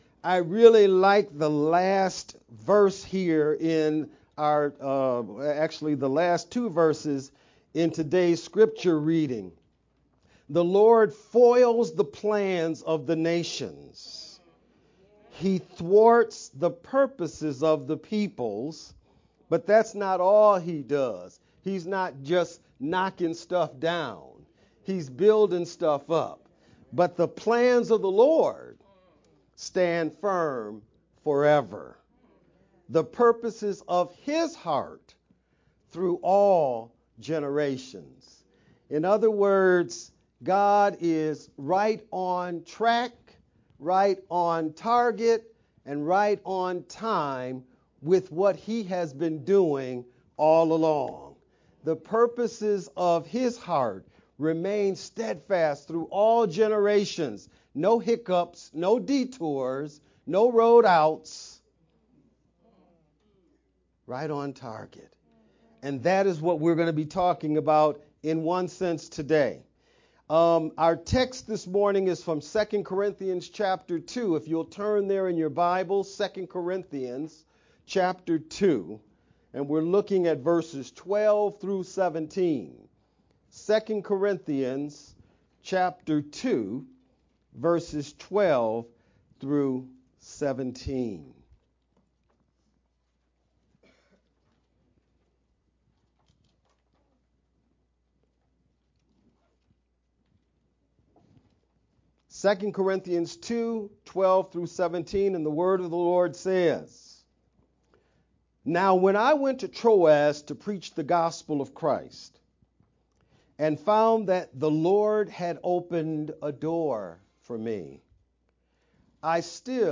March-17th-VBCC-Sermon-only-_Converted-CD.mp3